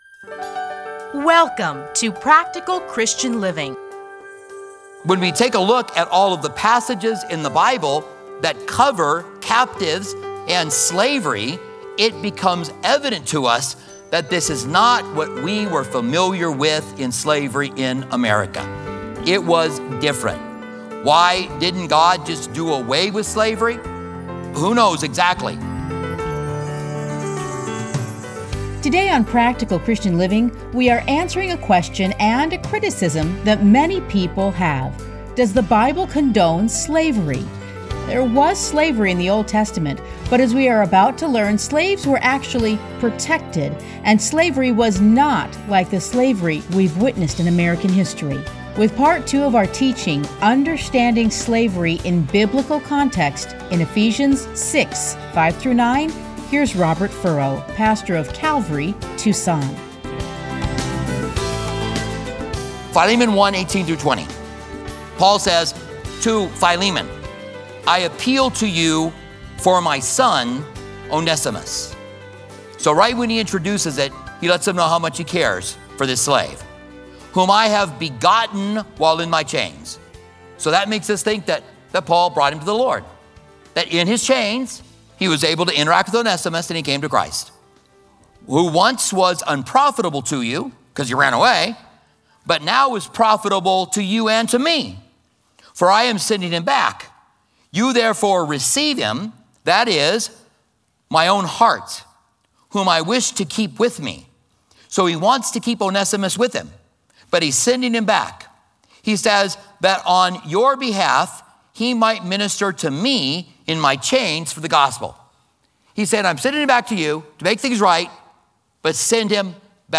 Listen to a teaching from Ephesians 6:5-9.